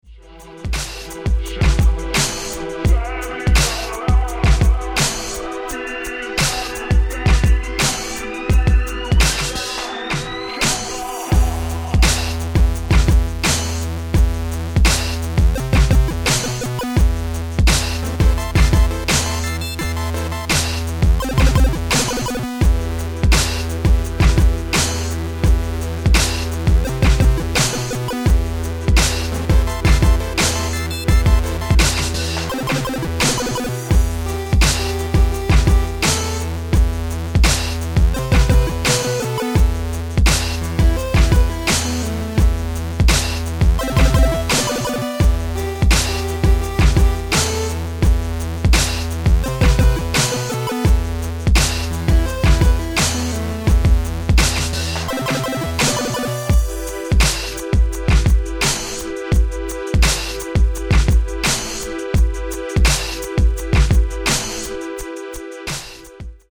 Electronix Bass